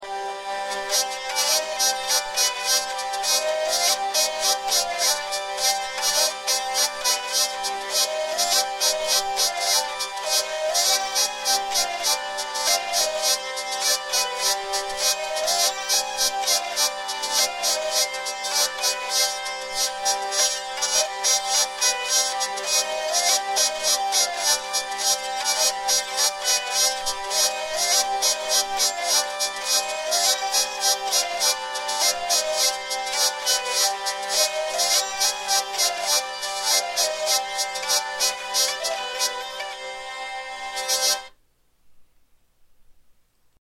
Au mieux, on trouvera ici des morceaux enregistrés avec des moyens modestes par un exécutant modeste.
L'enregistrement a été effectué en utilisant un micro branché directement sur l'ordinateur (voir fiche sur le paramétrage du micro).
Bourrée à deux temps traditionnelle.
Avec deux chanterelles.